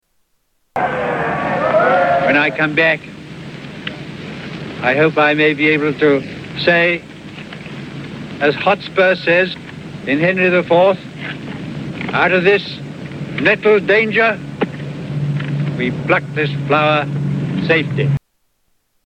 Tags: Historical Neville Chamberlain Audio Neville Chamberlain Speeches Arthur Neville Chamberlain Neville Chamberlain Sounds